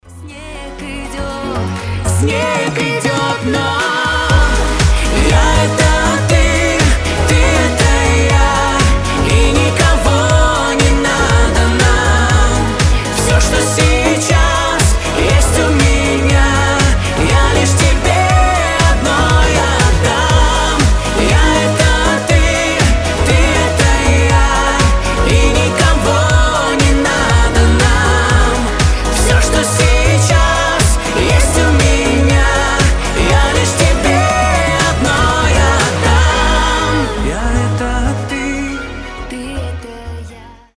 Дуэт , Cover
Поп